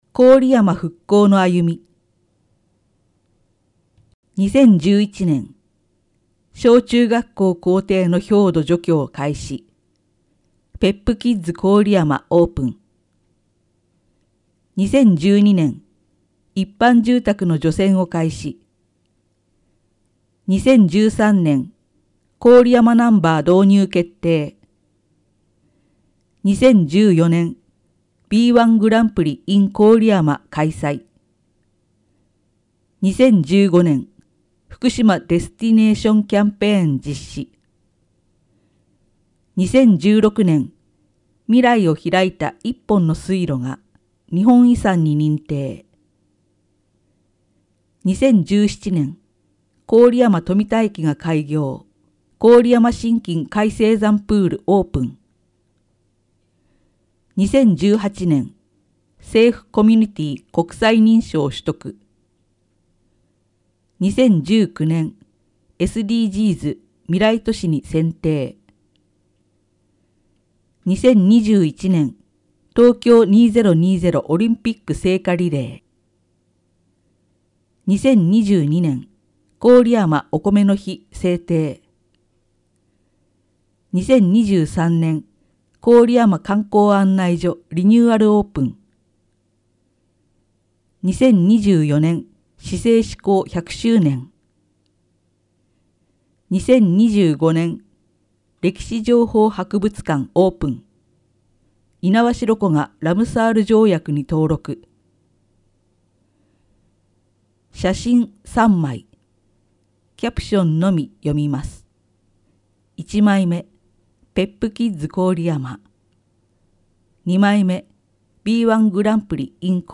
「声の広報」は、「広報こおりやま」の一部記事を「視覚障がい者支援ボランティアグループ　くるみ会」の皆さんが読み上げています。